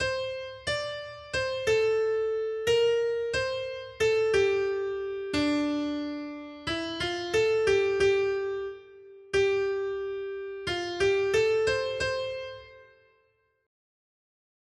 Noty Štítky, zpěvníky ol562.pdf responsoriální žalm Žaltář (Olejník) 562 Skrýt akordy R: Povstaň, Bože, suď zemi! 1.